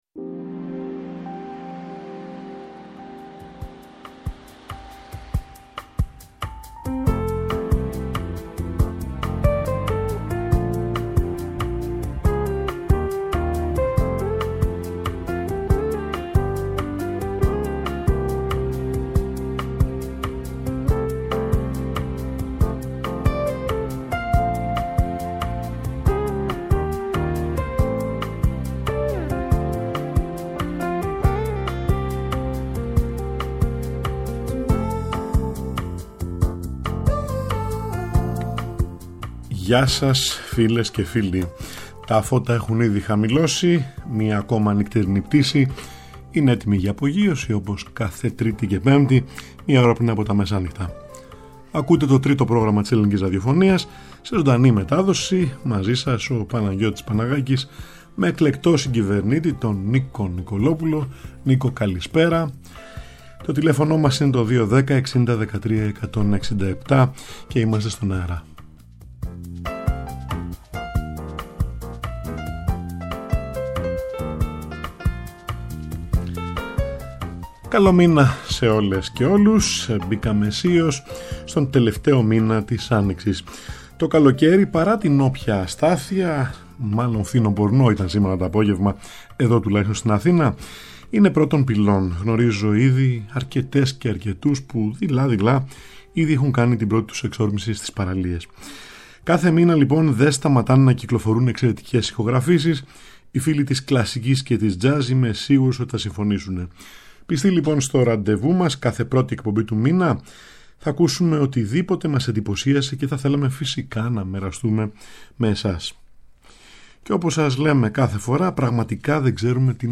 σύγχρονη μουσική
ηχογραφήσεις για πιάνο
jazz